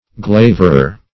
glaverer - definition of glaverer - synonyms, pronunciation, spelling from Free Dictionary Search Result for " glaverer" : The Collaborative International Dictionary of English v.0.48: Glaverer \Glav"er*er\, n. A flatterer.